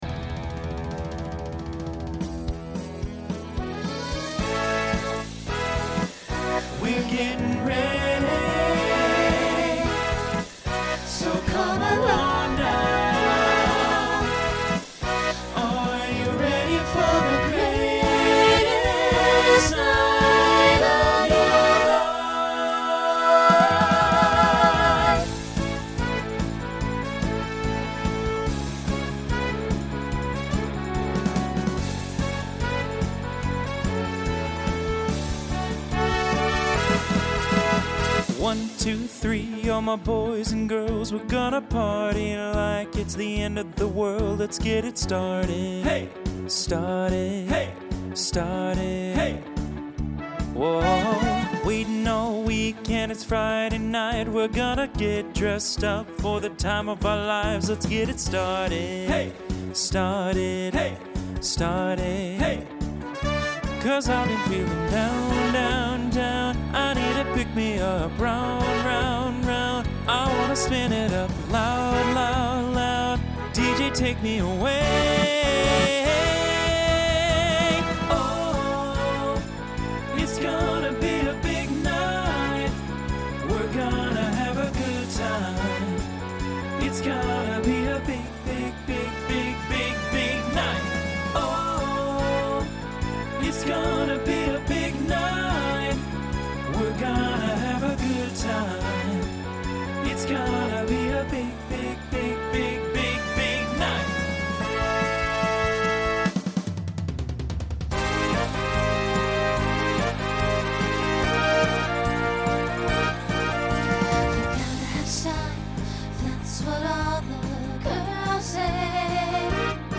2010s Genre Pop/Dance
Voicing Mixed